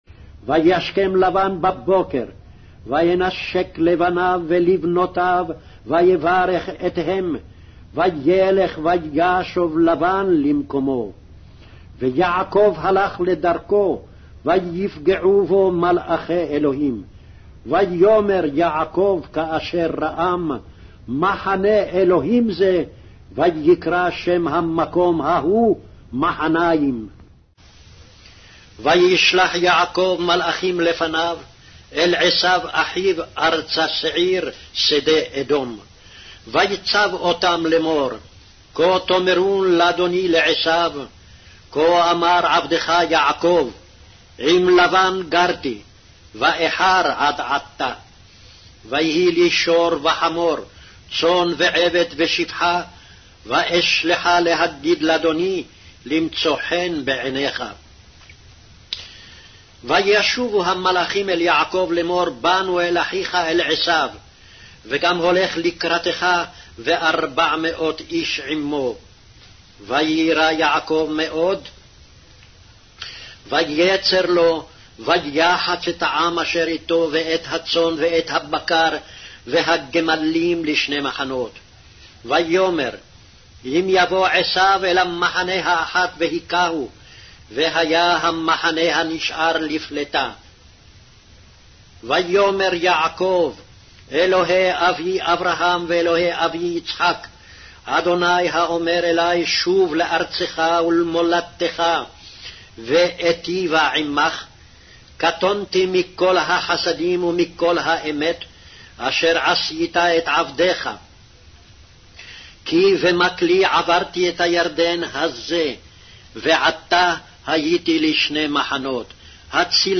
Hebrew Audio Bible - Genesis 15 in Gnttrp bible version